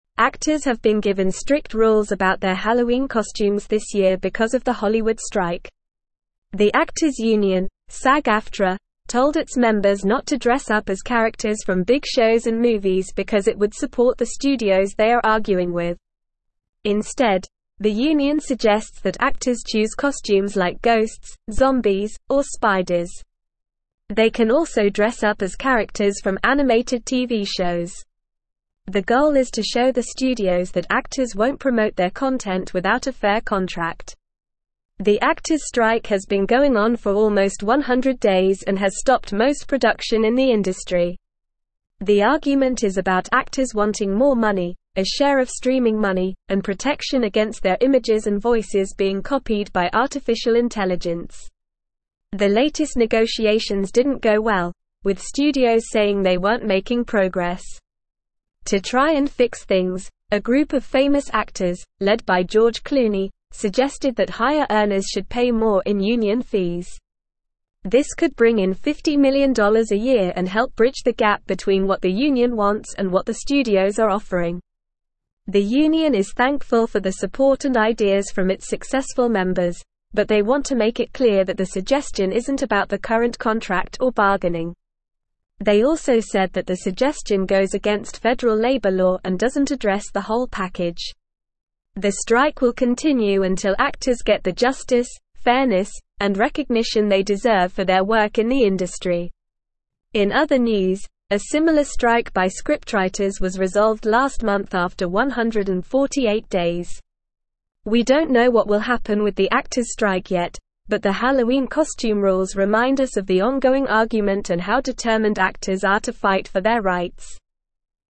Normal
English-Newsroom-Upper-Intermediate-NORMAL-Reading-Hollywood-Actors-Given-Halloween-Costume-Rules-Amid-Strike.mp3